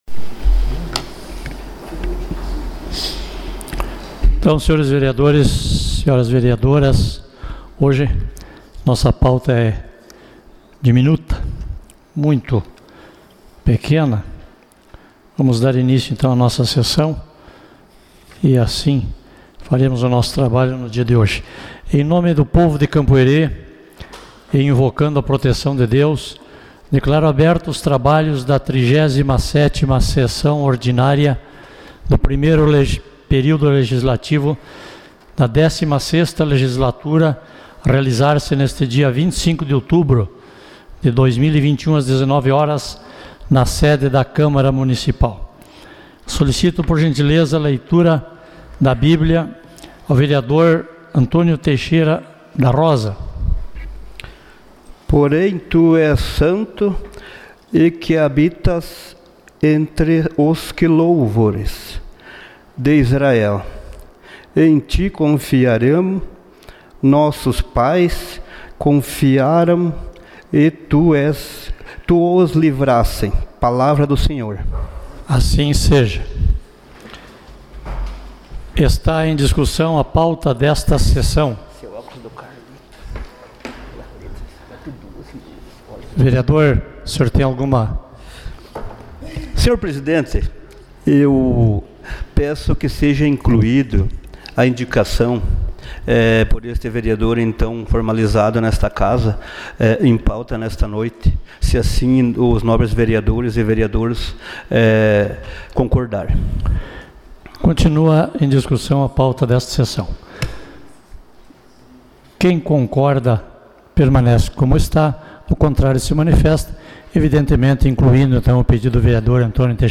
Sessão Ordinária 25 de outubro de 2021